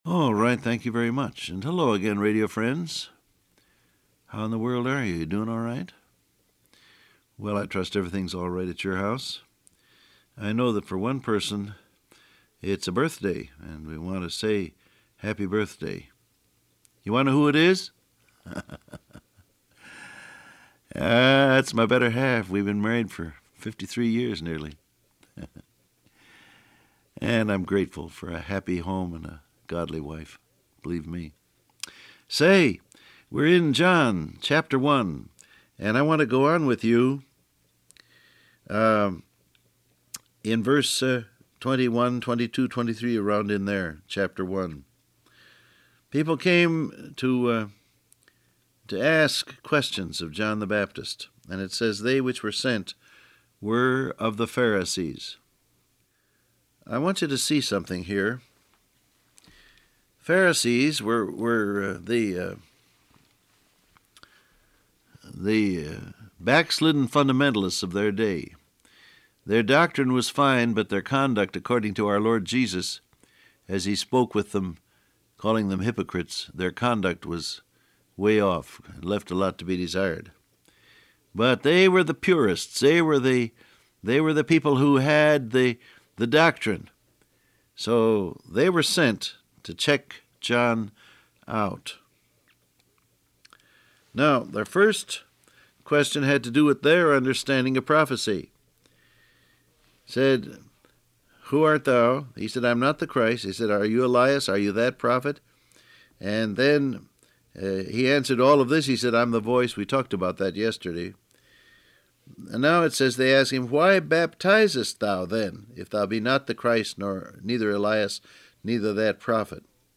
Download Audio Print Broadcast #6808 Scripture: John 1:19-29 , Isaiah 53:7, Revelation 7:9 Topics: Pray , Point To Jesus , Baptize , Lamb Transcript Facebook Twitter WhatsApp Alright, thank you very much.